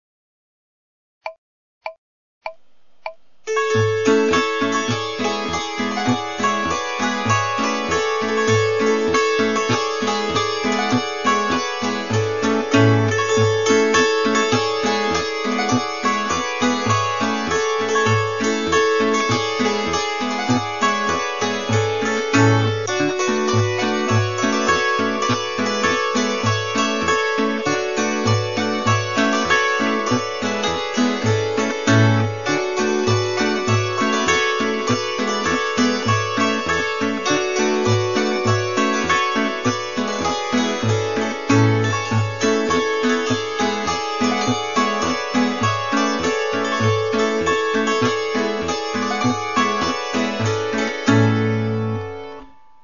Wäscheleinen Schottisch